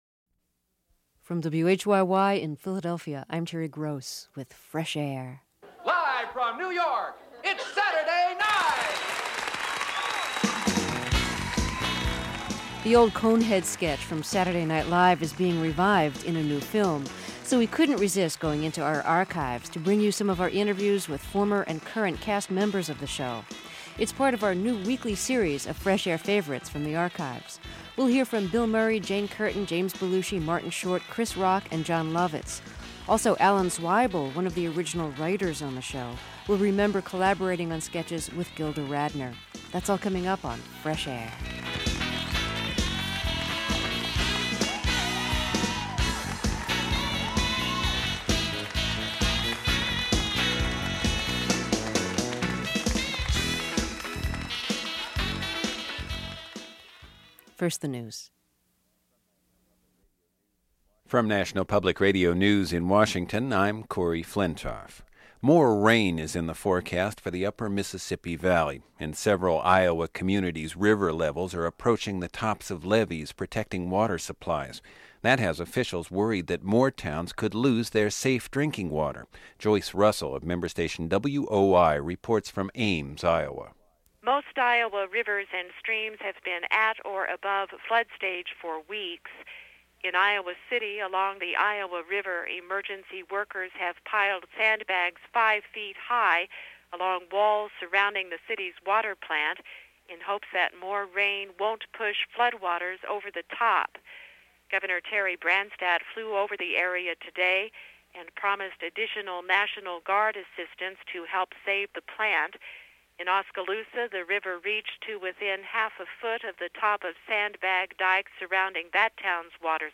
Interview Martin Short